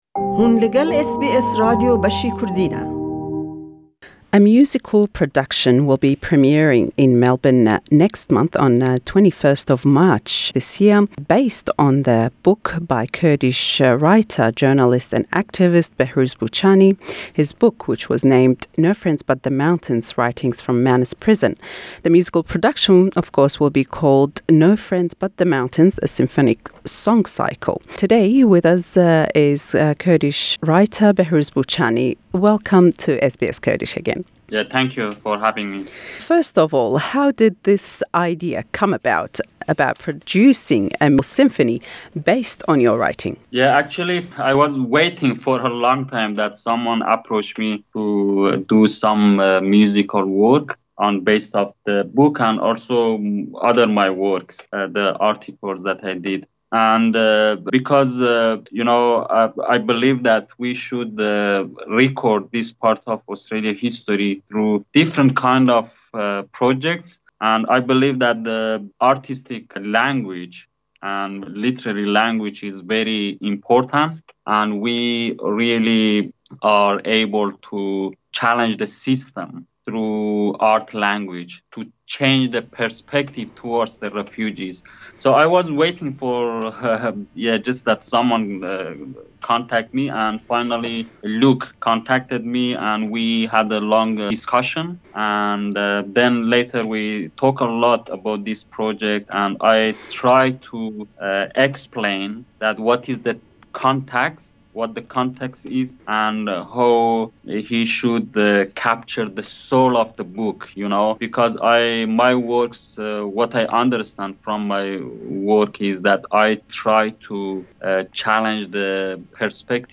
In this Interview we ask Mr Boochani about the symphonic production, and what the audience can expect from this performance.